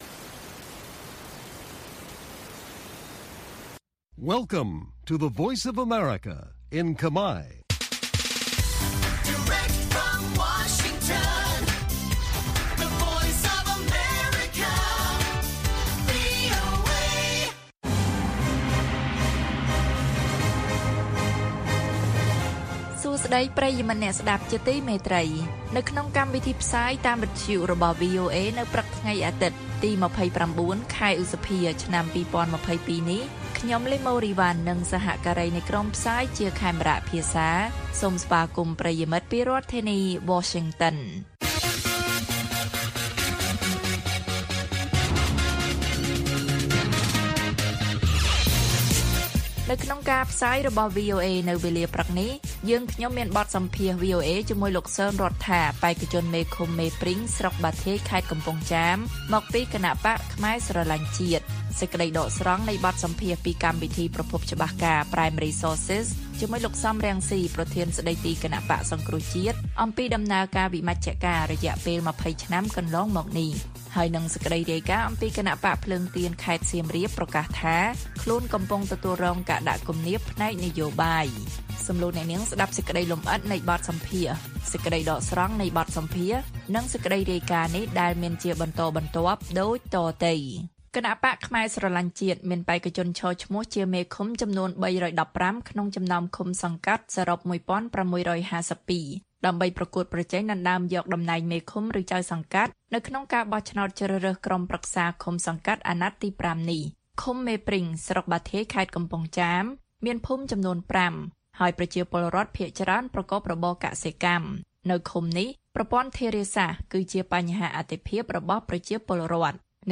ព័ត៌មានពេលព្រឹក ២៩ ឧសភា៖ គណបក្សភ្លើងទៀនខេត្តសៀមរាបប្រកាសថា ខ្លួនកំពុងទទួលរងការដាក់គំនាបផ្នែកនយោបាយ
បទសម្ភាសន៍